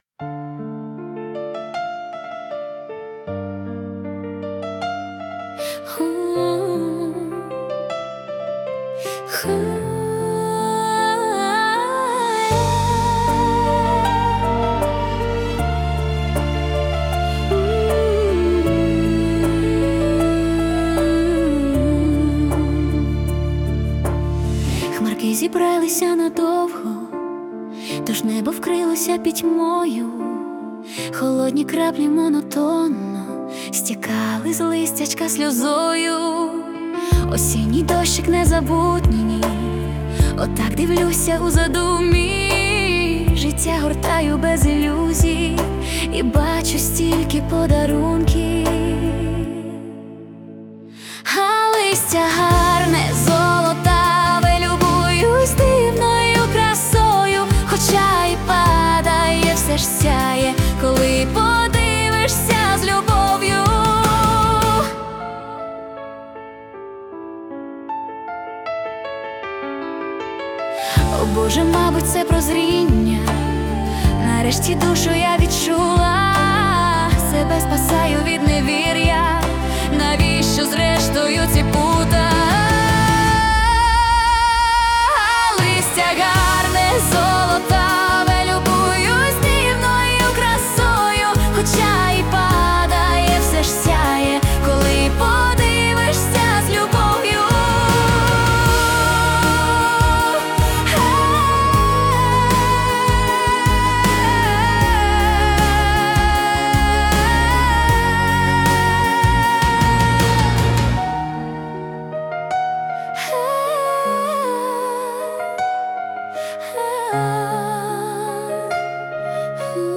Музична композиція створена за допомогою SUNO AI
СТИЛЬОВІ ЖАНРИ: Ліричний
Чудова пісня, мелодія чуттєва на дуже гарні душевні слова.